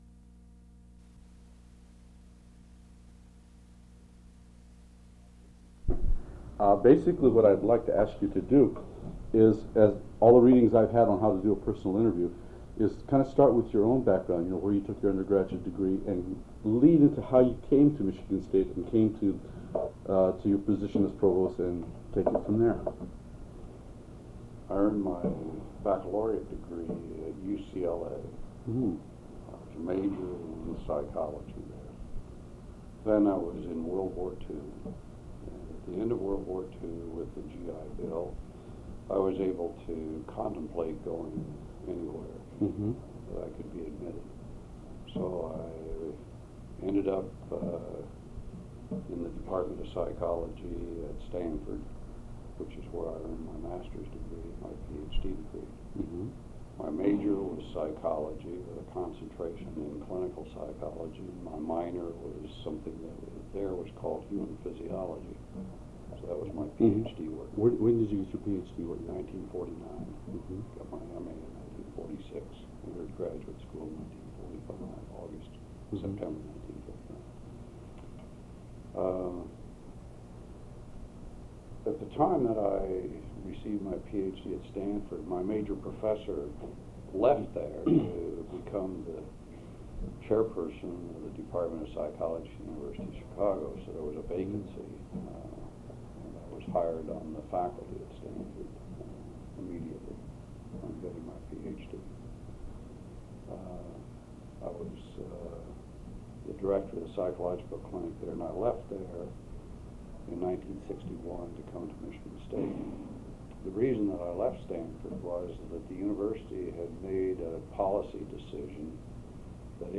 Subjects: Faculty
Date: June 28, 1990 Format: Audio/mp3 Original Format: Audio cassette tape Resource Identifier: A008659 Collection Number: UA 10.3.156 Language: English Rights Management: Educational use only, no other permissions given.